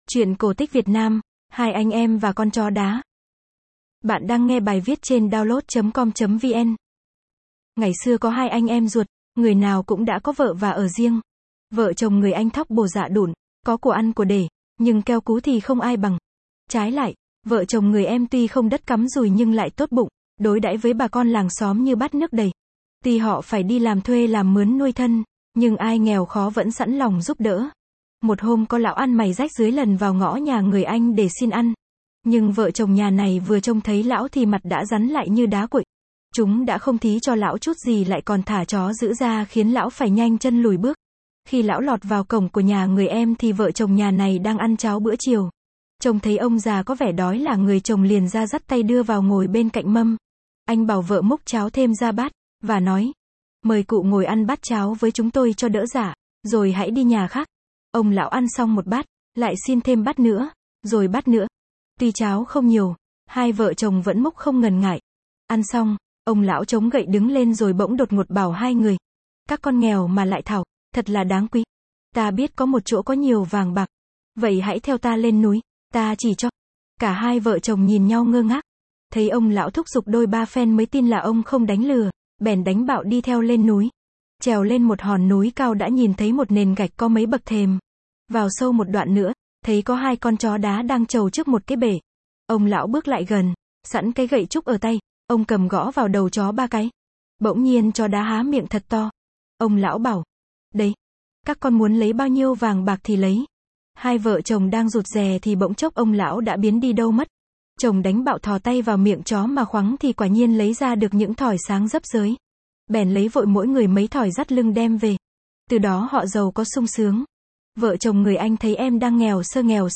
Sách nói | Hai anh em và con chó đá